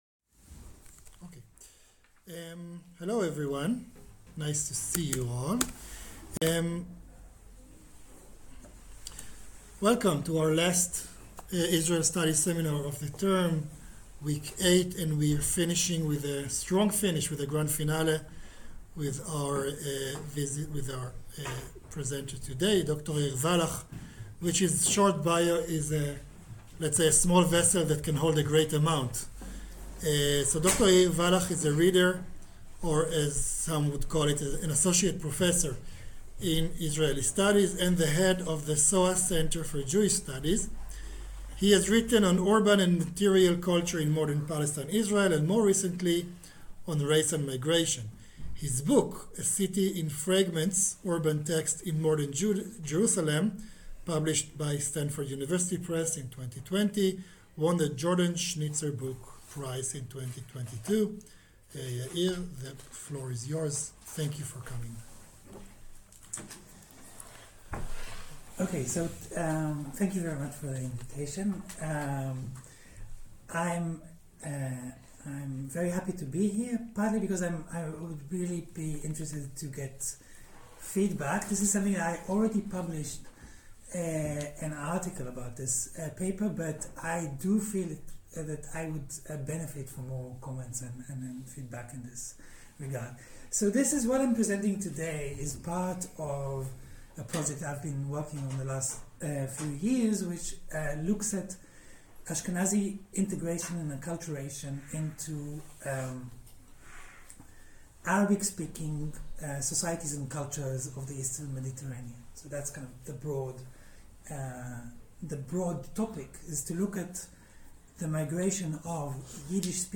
Israel Studies Seminar